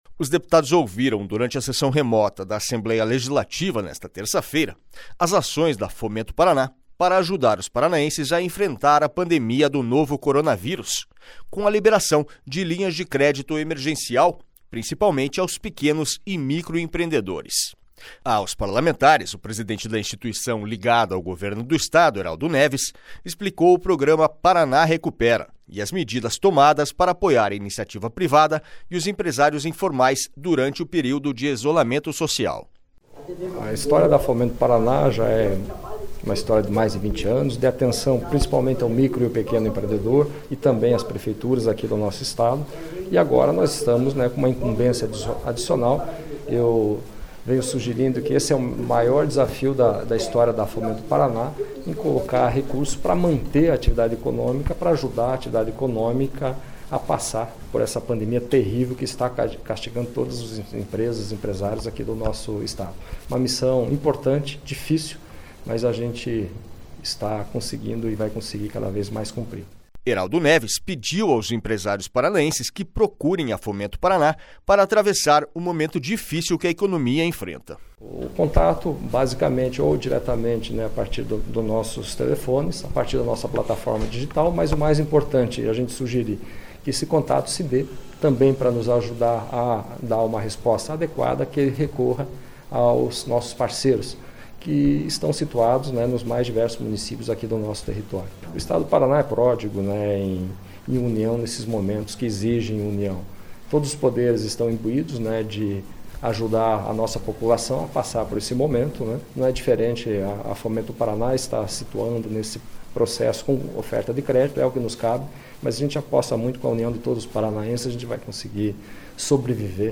Os deputados ouviram durante a sessão remota da Assembleia Legislativa, nesta terça-feira (12), as ações da Fomento Paraná para ajudar os paranaenses a enfrentar a pandemia do novo coronavírus, com a liberação de linhas de crédito emergencial, principalmente, aos pequenos e micro empreendedores.
SONORA HERALDO NEVES